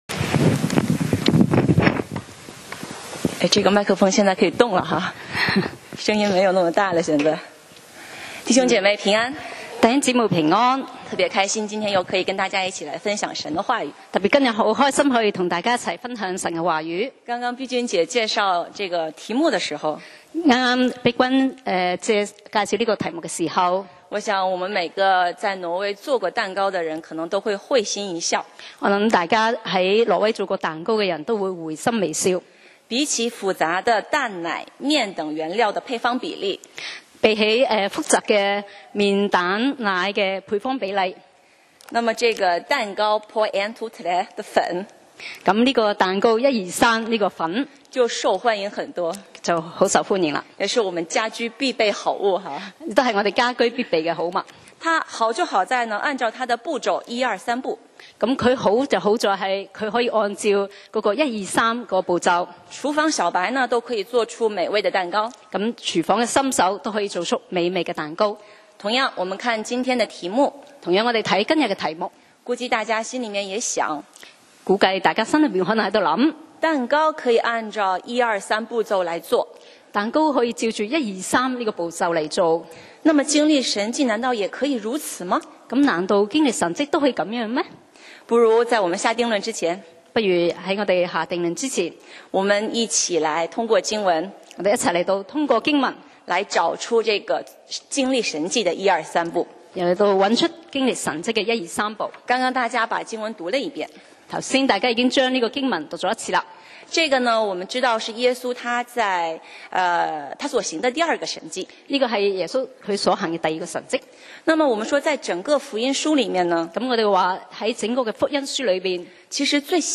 講道 Sermon 題目 Topic：经历神迹1-2-3 經文 Verses：约翰福音4：43-54. 43过了那两天，耶稣离了那地方，往加利利去。